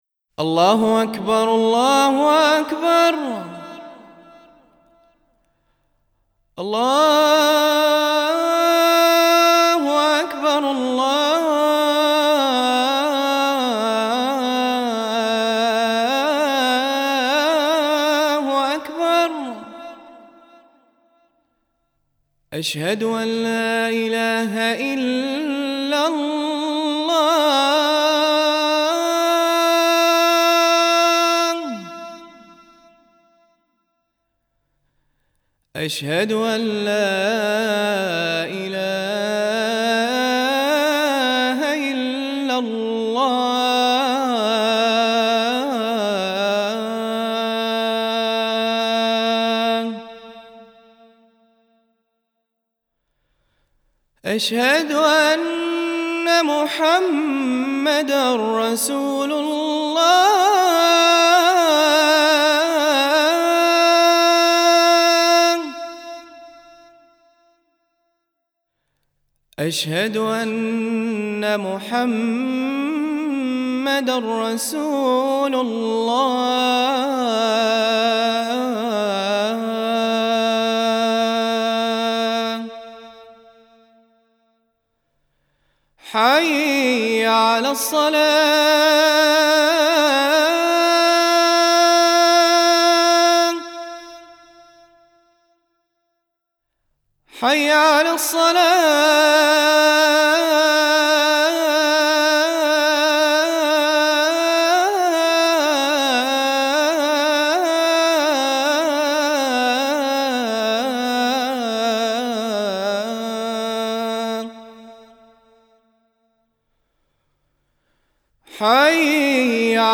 عنوان المادة أذان